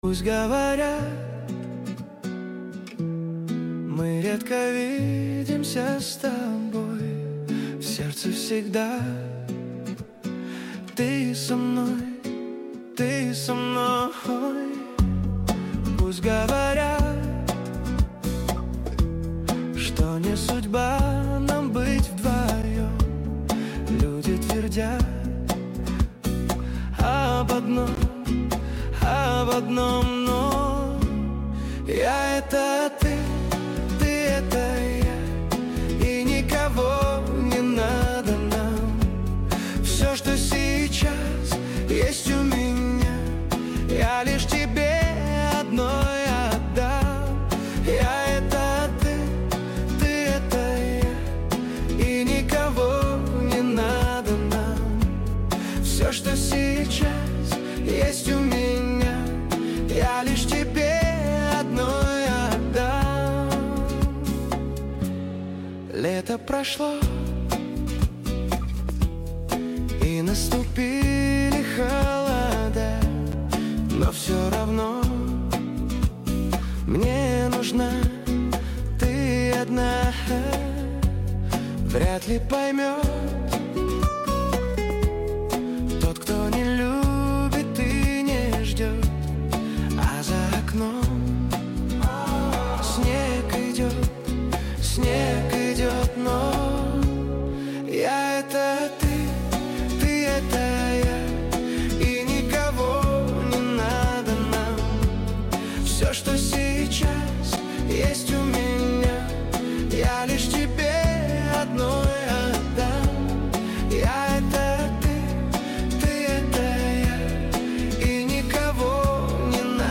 Качество: 128 kbps, stereo
Каверы 2025, Песни Суно ИИ